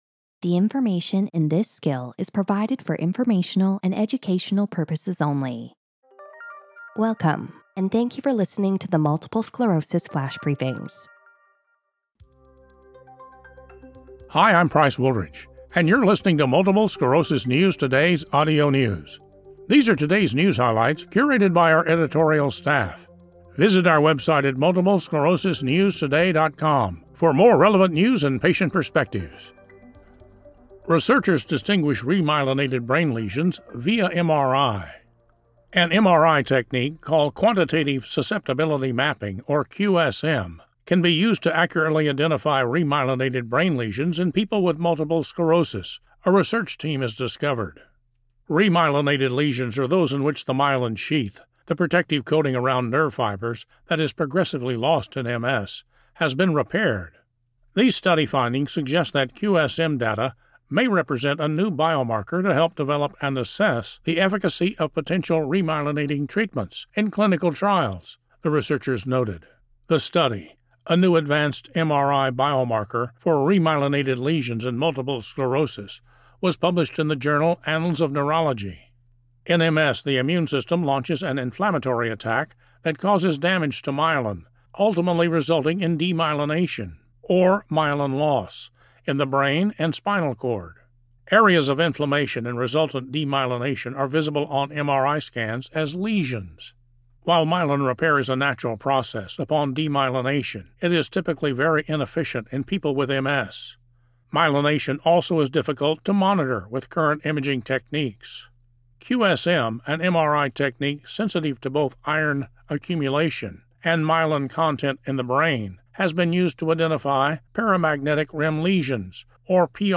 reads a news article